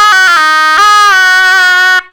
SHENNAI1  -R.wav